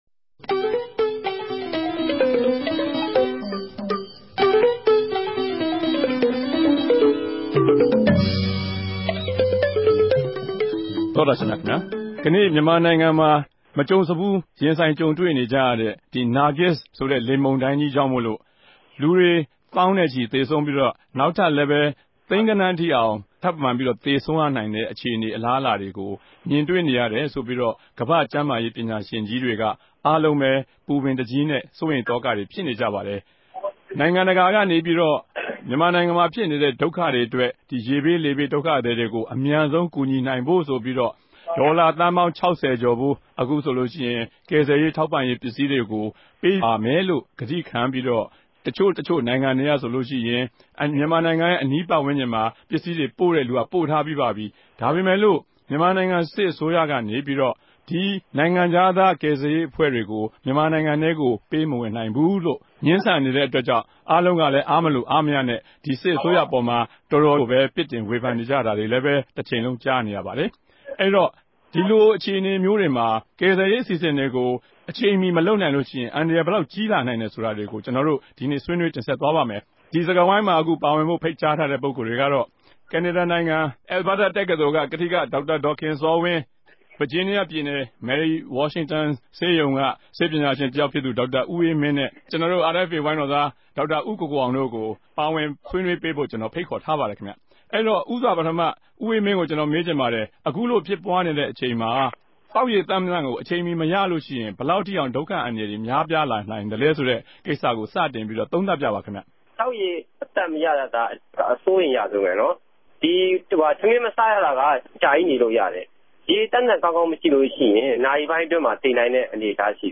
တနဂဿေိံြ ဆြေးေိံြးပြဲ စကားဝိုင်း။